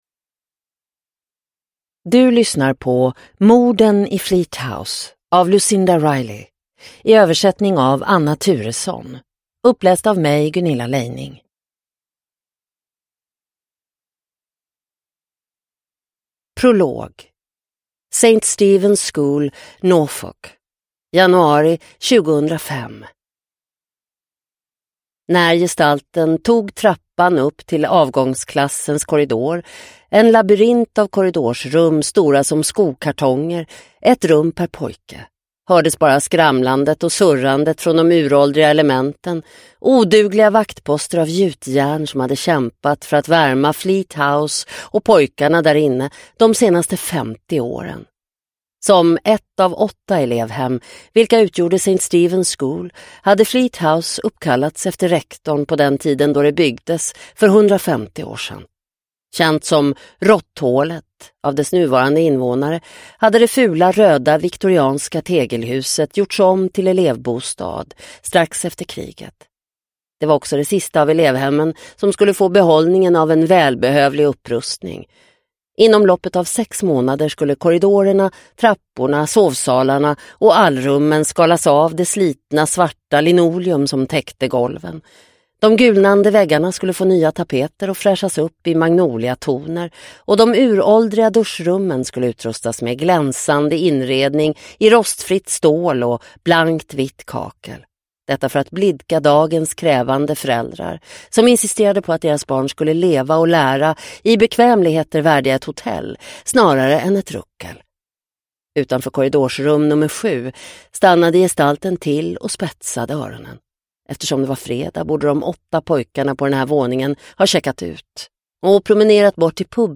Morden i Fleat House – Ljudbok – Laddas ner